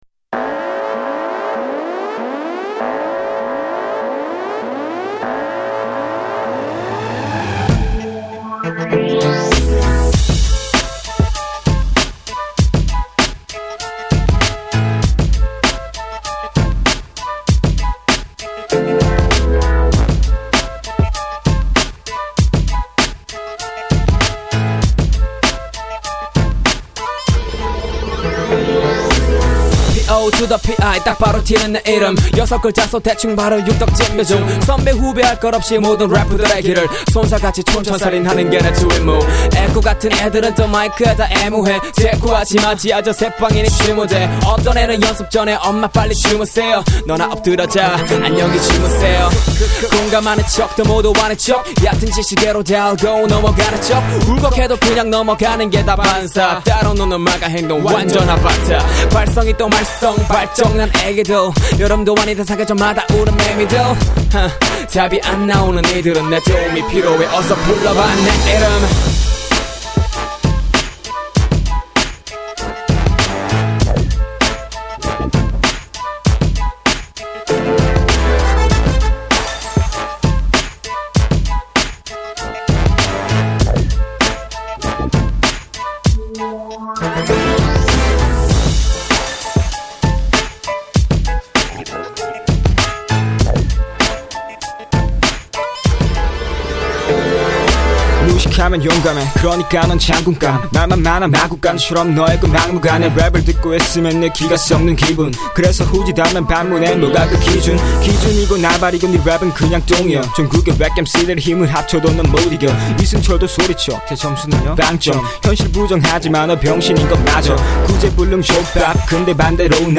• [REMIX.]
녹음할때 첫번째로 녹음한 곡이라 많이 떨면서해서 실수도 많이하고
가사랑 플로우자체에도 문제가 많았다고 생각되는 곡입니다.